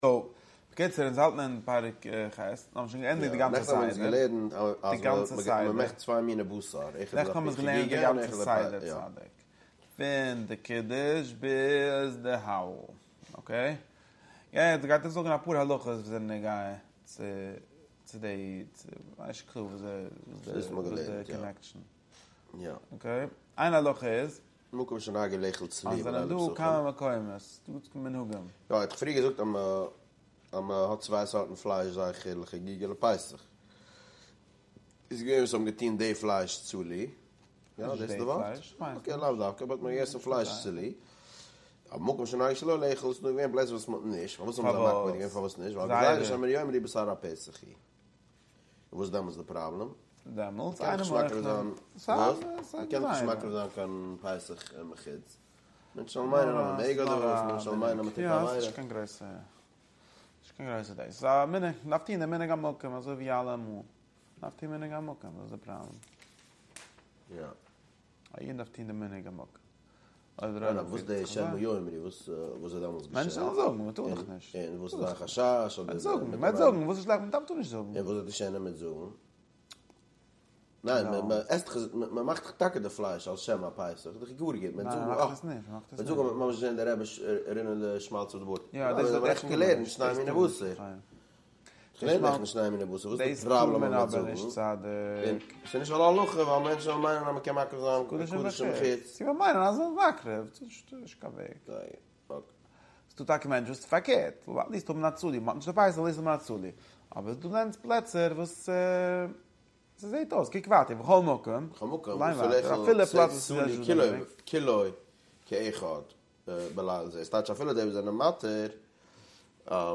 דער שיעור לערנט הלכות פסחים פרק ח' אין רמב"ם, וואו ס'ווערט באהאנדלט די מנהגים וועגן עסן צלי בליל הסדר, די דין פון מי שאין לו יין און צי מען קען נוצן חמר מדינה פאר ד' כוסות, און די סדר פון אכילת מצה ומרור. ס'ווערט אויך דורכגענומען די פונדאמענטאלע שאלה צי די אפיקומן איז א באזונדערע מצוה אדער א המשך פון די ערשטע אכילת מצה, און די דין פון איינער וואס שלאפט איין בתוך הסעודה און צי ער קען ווייטער עסן.